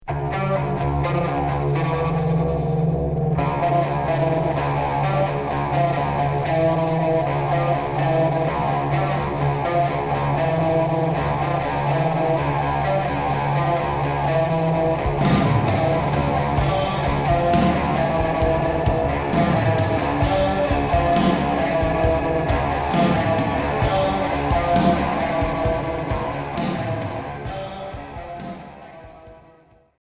Rock cold wave Unique Maxi 45t retour à l'accueil